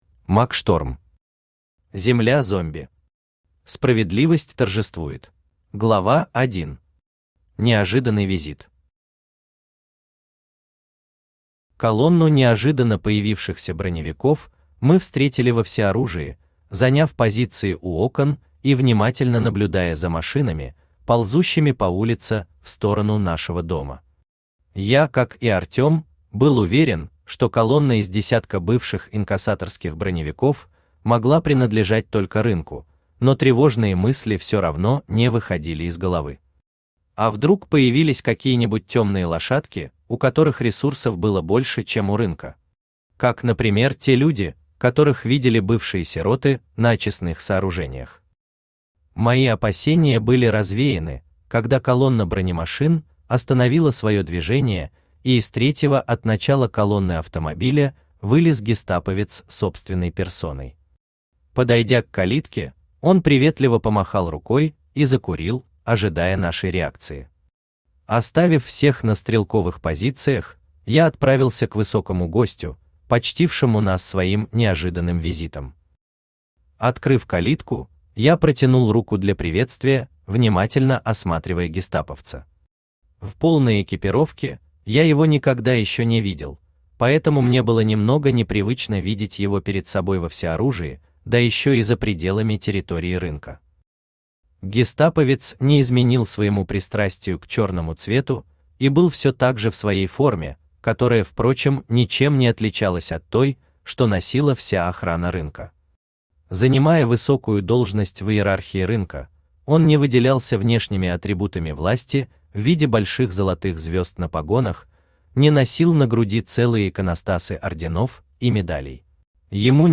Аудиокнига Земля зомби. Справедливость торжествует | Библиотека аудиокниг
Справедливость торжествует Автор Мак Шторм Читает аудиокнигу Авточтец ЛитРес.